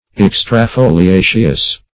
Search Result for " extrafoliaceous" : The Collaborative International Dictionary of English v.0.48: Extrafoliaceous \Ex`tra*fo`li*a"ceous\, a. [Pref. extra- + foliaceous.]
extrafoliaceous.mp3